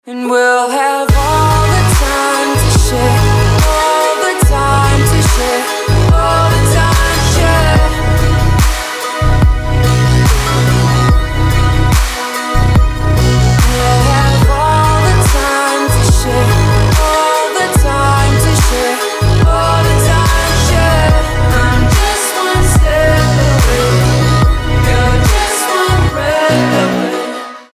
• Качество: 320, Stereo
Downtempo
vocal
electonic